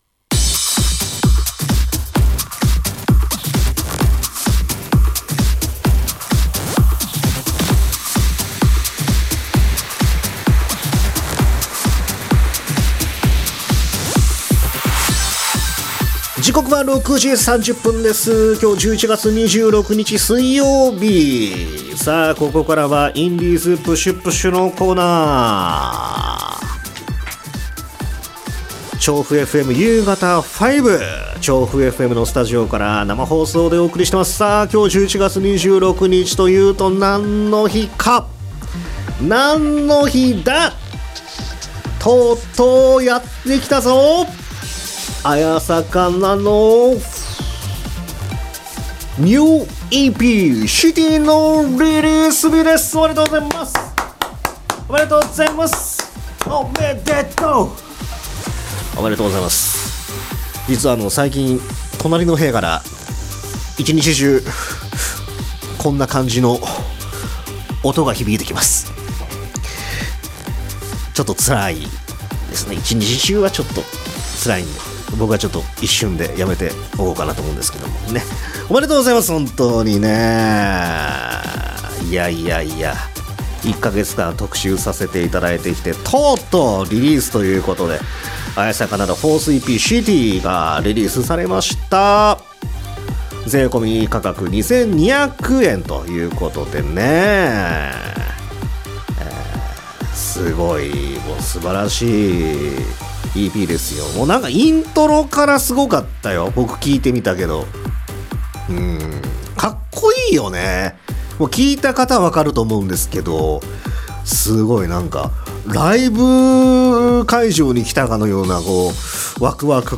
2025年11月度のインディーズPUSH×2は… 9か月ぶり！3回目！且つ初の生出演！の？2週間後！